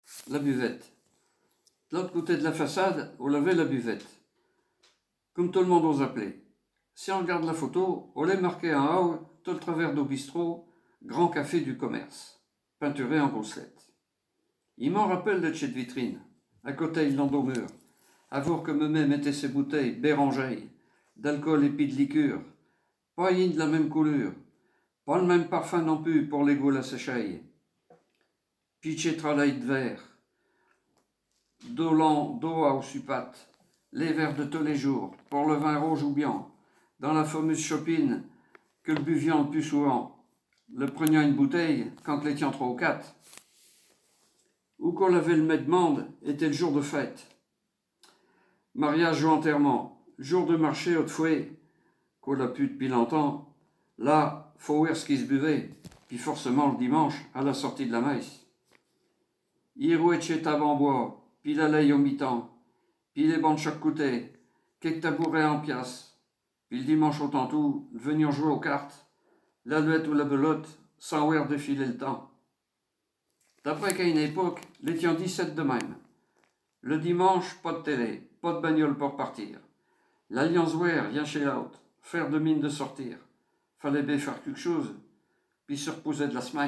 Poésies en patois